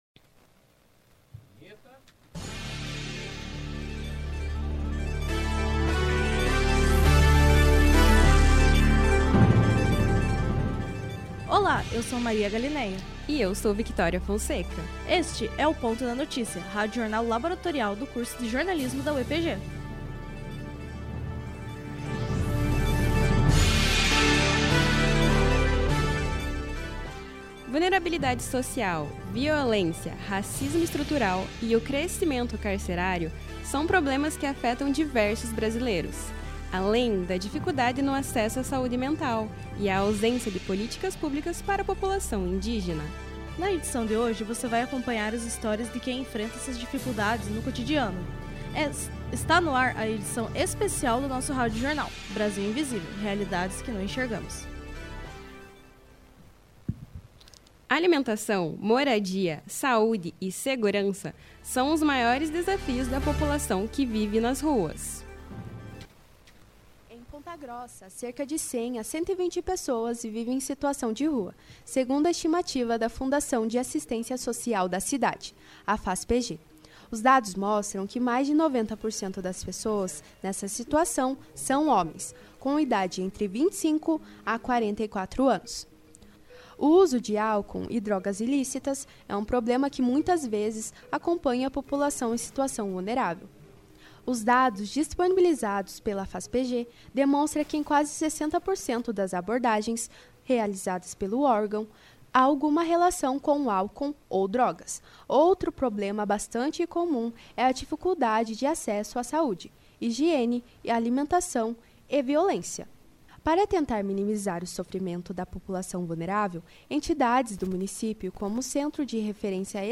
radiojornalpronto.mp3